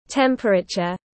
Temperature /ˈtem.prə.tʃər/